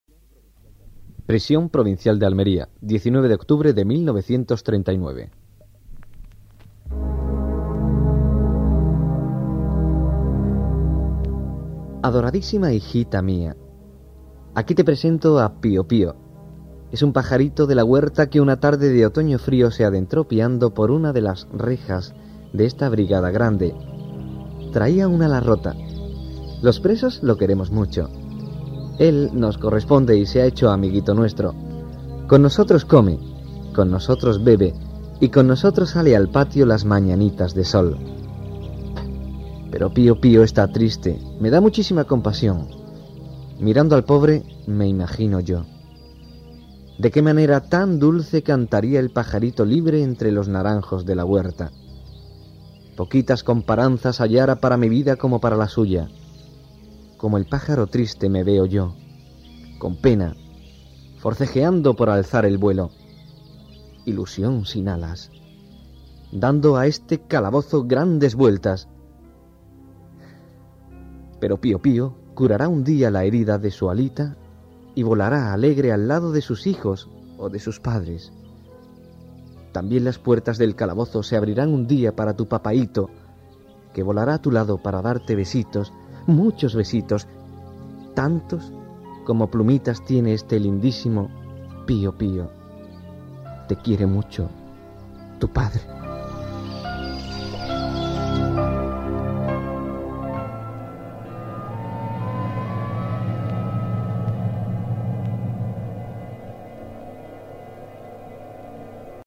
por Audio descripción de la postal "Pio-Pio".